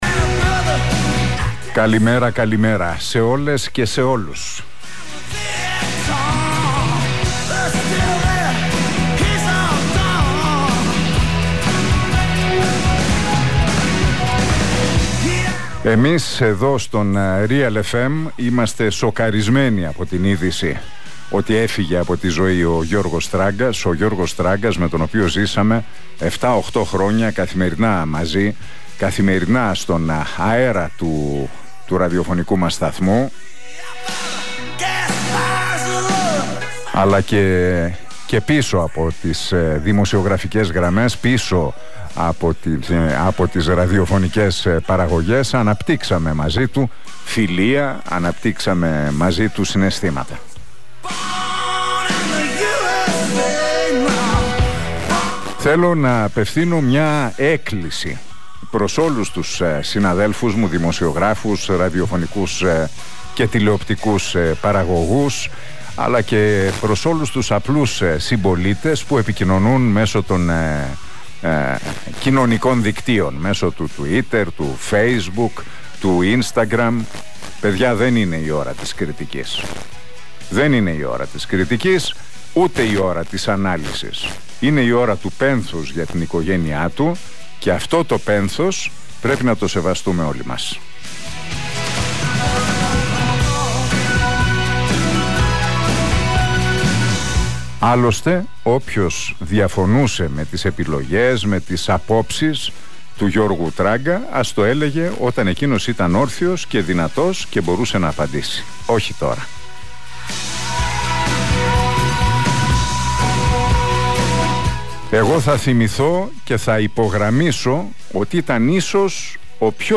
Το δικό του αντίο στον Γιώργο Τράγκα είπε ο Νίκος Χατζηνικολάου μέσα από την εκπομπή του στον Realfm 97,8.